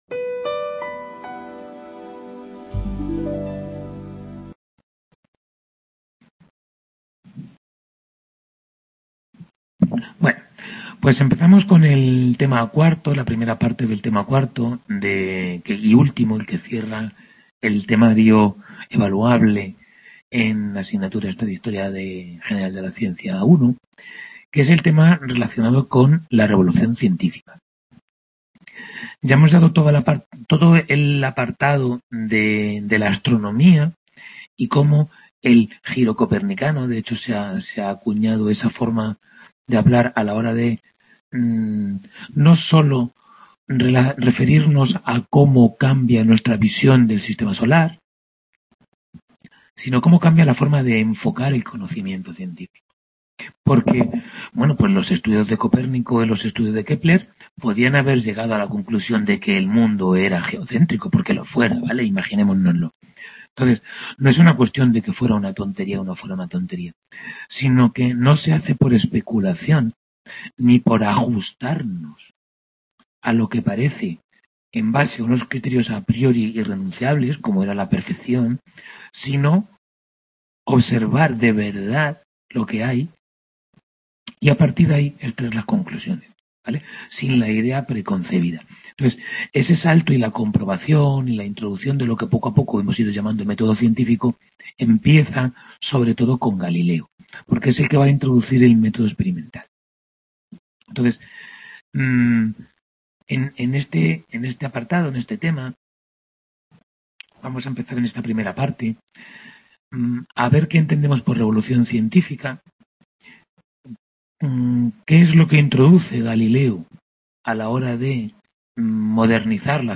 Tutoría 9 de Historia General de la Ciencia I | Repositorio Digital